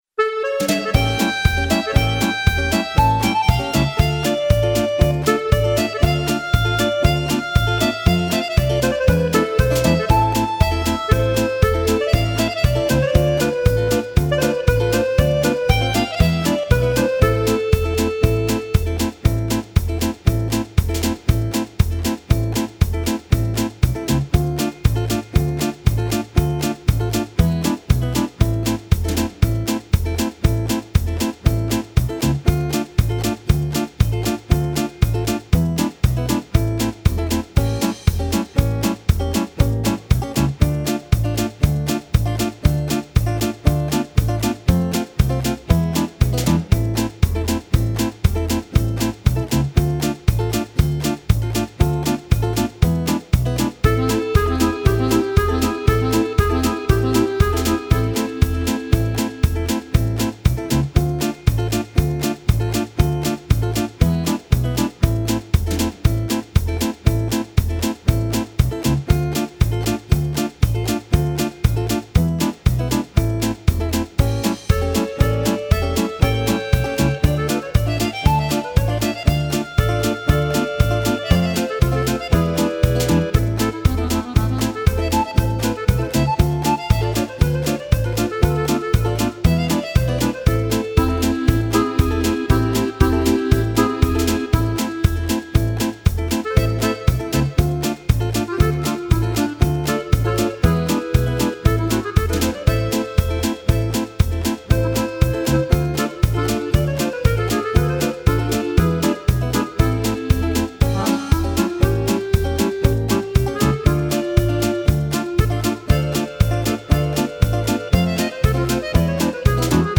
Вступление - классное, а дальше только ритм, без мелодии.